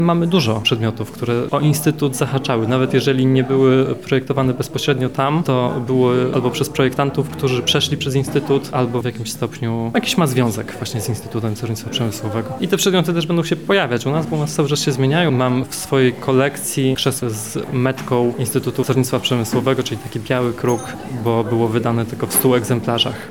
Wydarzenie odbyło się w galerii LubVintage.